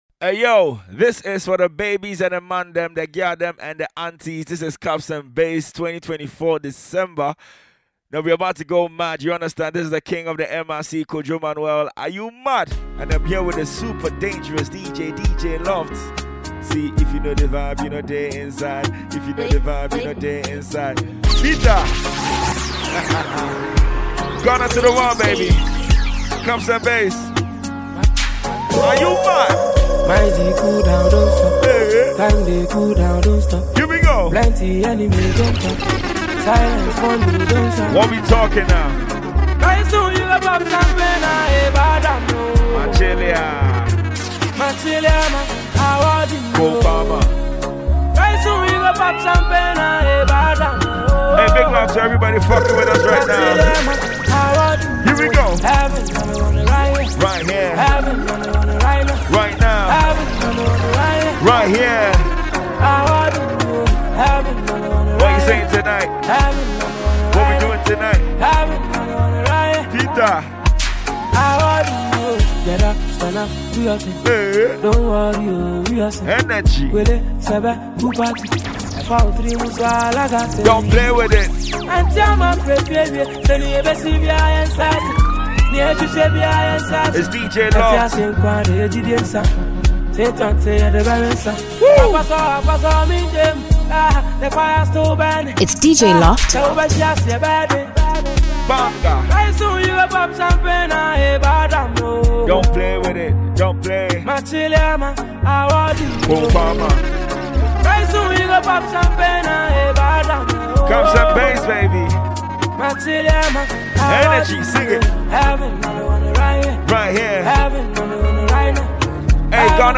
mixtape
DJ Mixtape
Ghana Afrobeat MP3